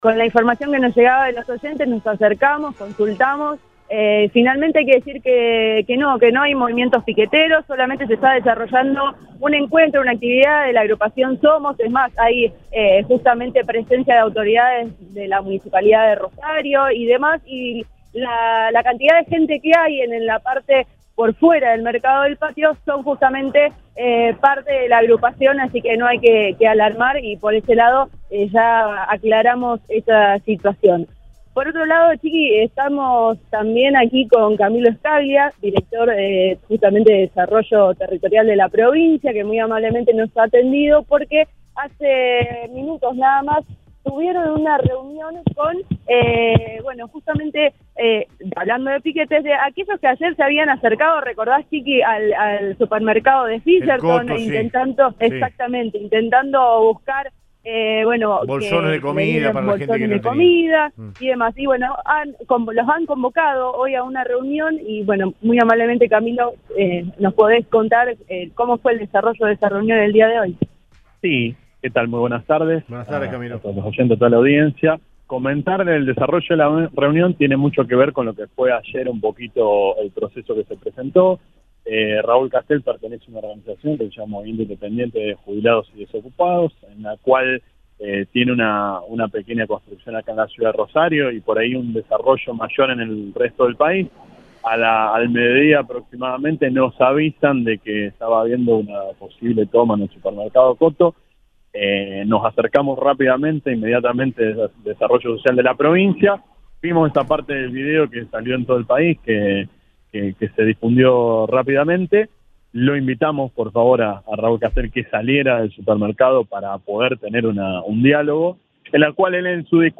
El director Provincial de Desarrollo Territorial habló sobre la protesta por comida.